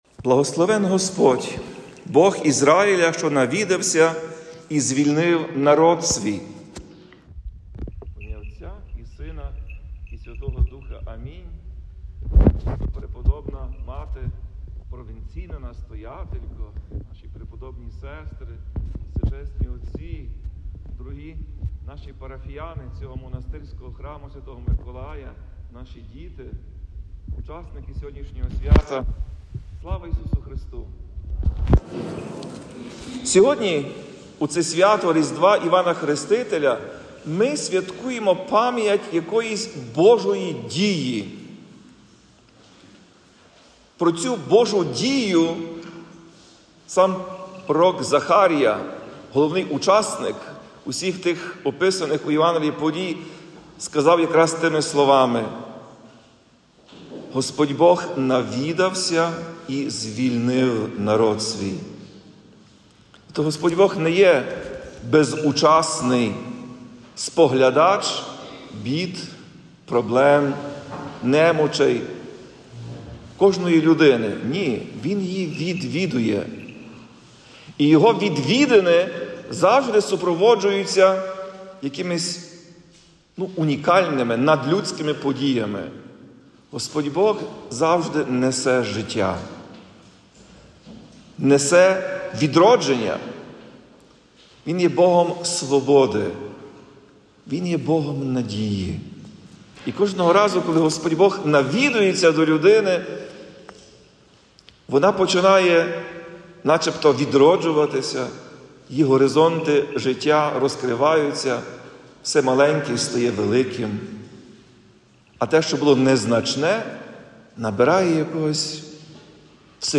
Про це зазначив Отець і Глава УГКЦ Блаженніший Святослав у проповіді у свято Різдва Івана Хрестителя, 24 червня, під час Архиєрейської Божественної Літургії в монастирській церкві Святого Миколая Згромадження сестер Пресвятої родини у Львові.